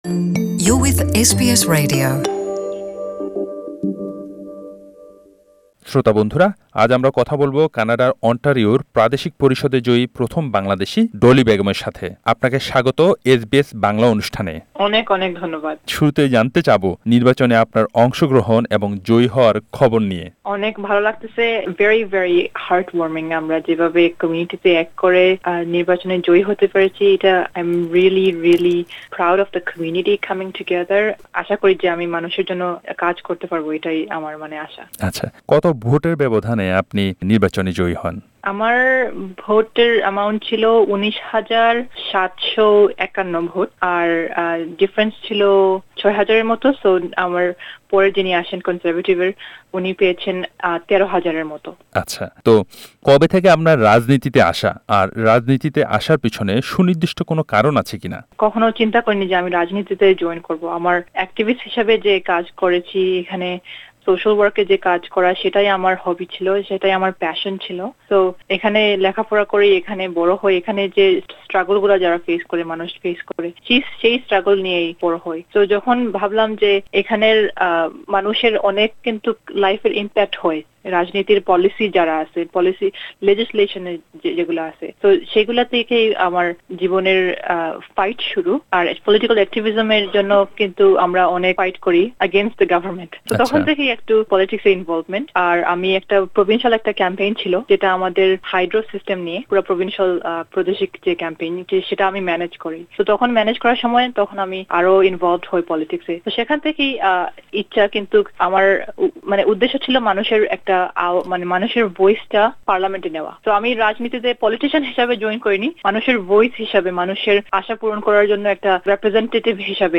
পুরো কথোপকথন শুনতে উপরের অডিও লিঙ্কে ক্লিক করুন।